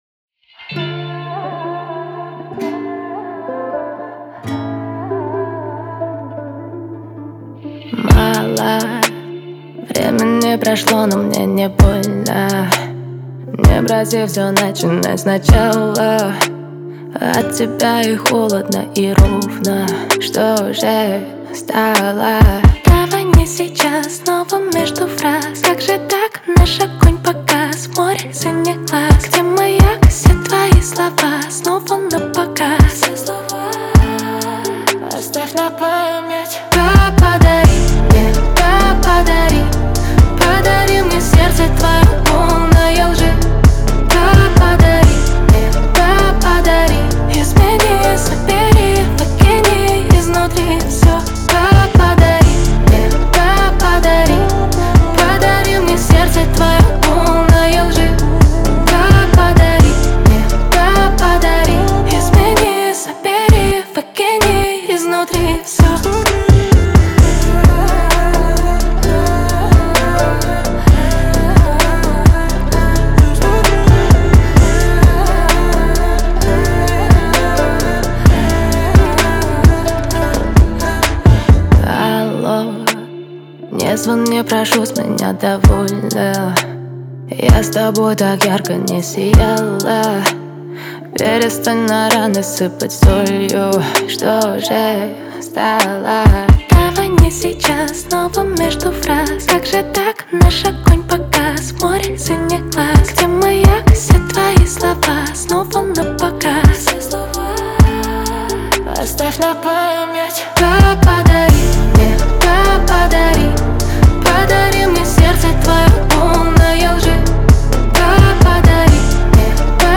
плавный вокал